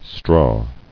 [straw]